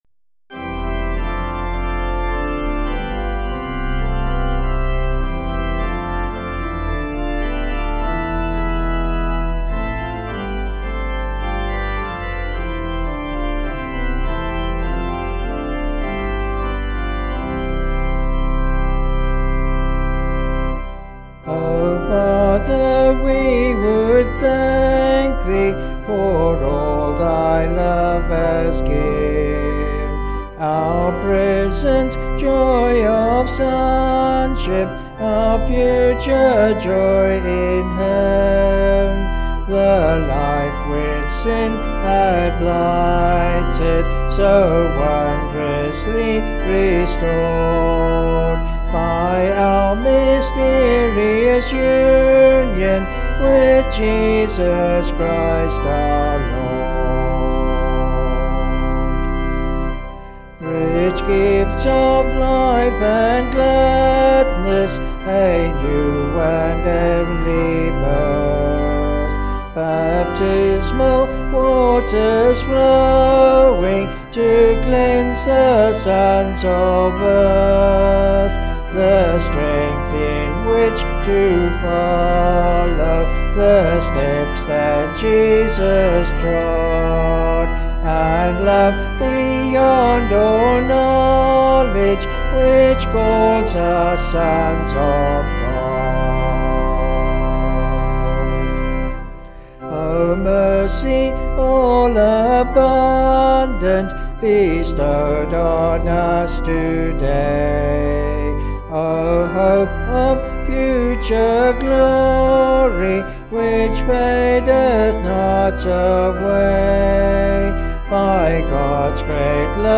Vocals and Organ   264.2kb Sung Lyrics